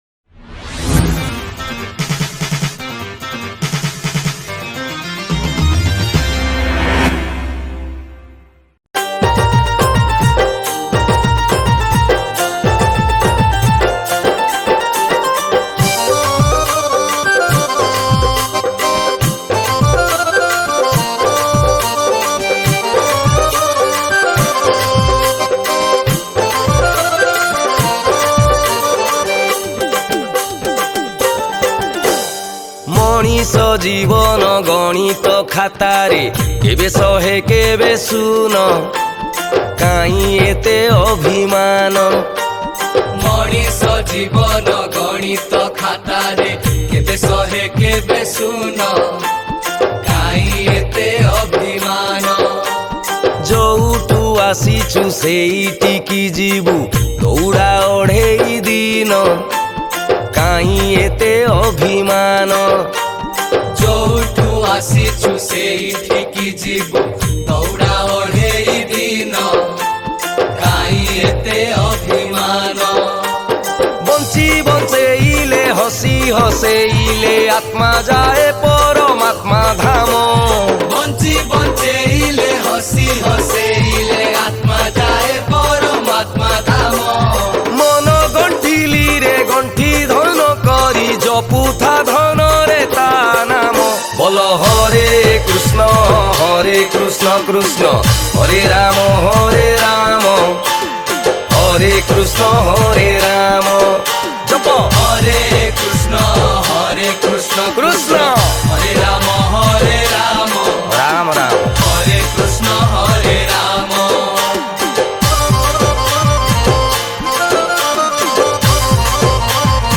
Kartik Purnima Song Songs Download